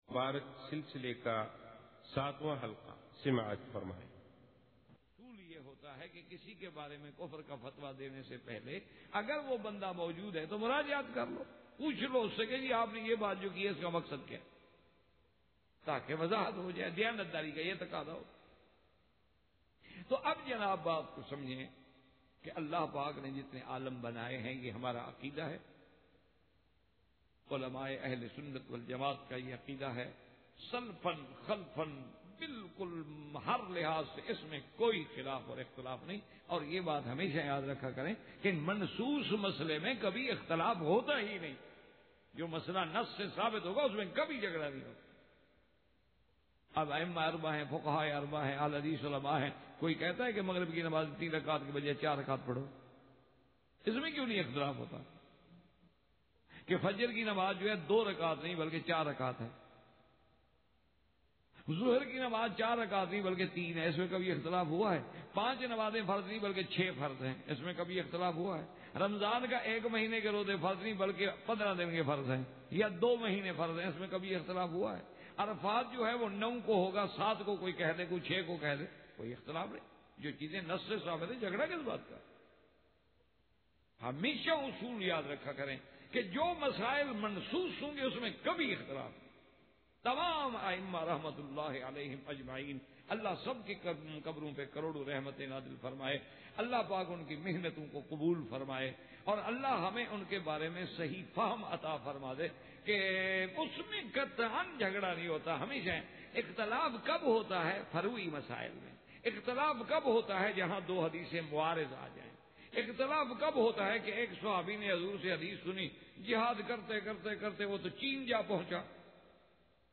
He is a traditional classical scholar. He hold his majlis everyday, after magrib in front of the Kaabah at Bab al-Umrah for the Indo-Pak people.